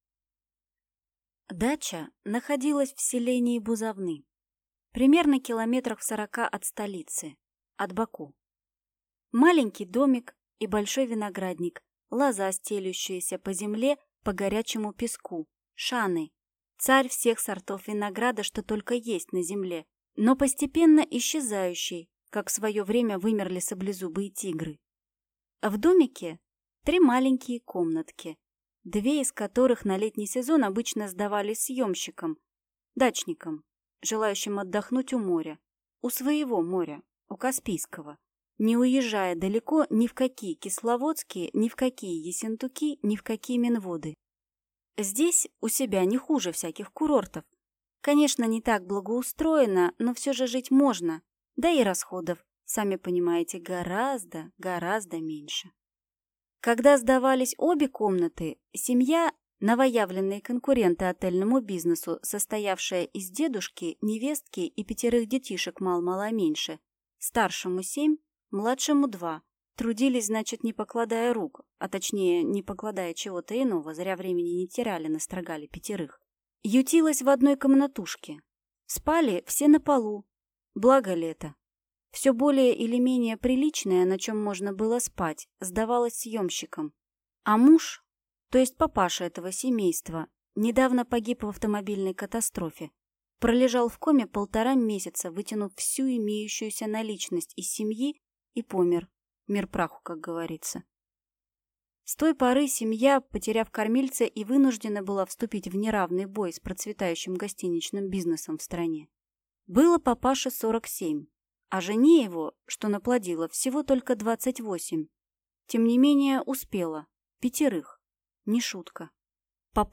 Аудиокнига Псих | Библиотека аудиокниг